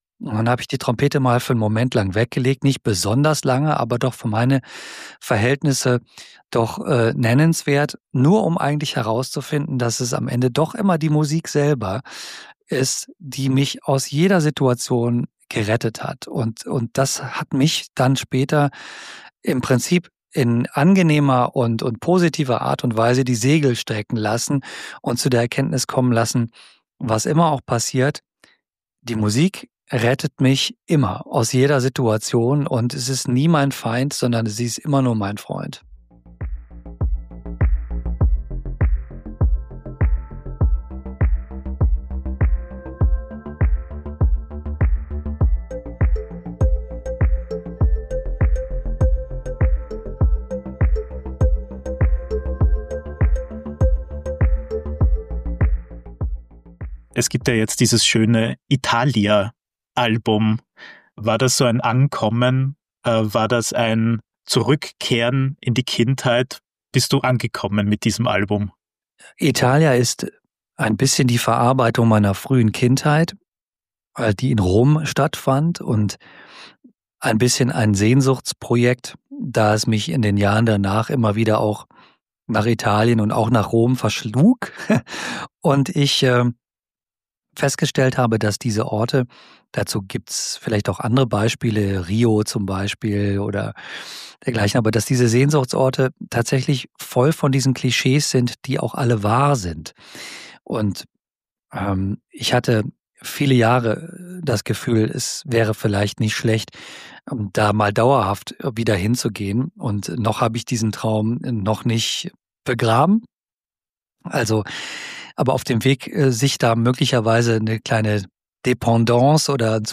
Ein Gespräch über Klang und Charakter, über Gelassenheit und Leidenschaft und darüber, wie man mit einer Trompete ein Stück “Italia” einfängt.